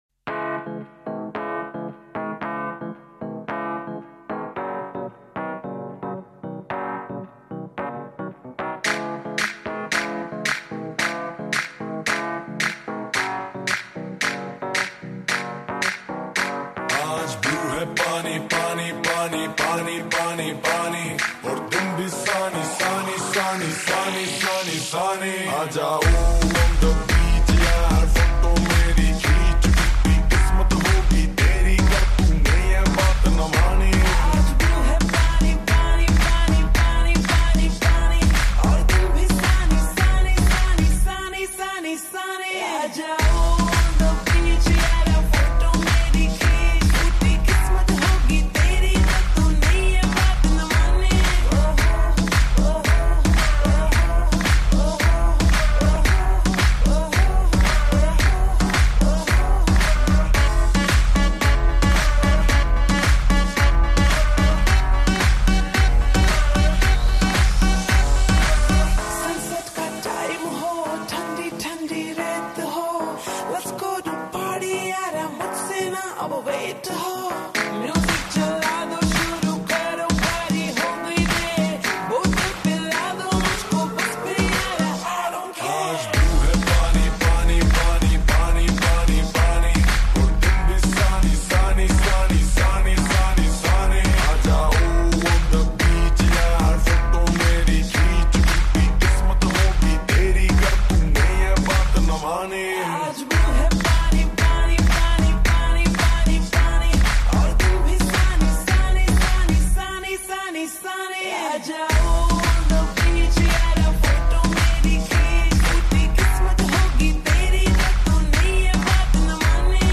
slow+Reverb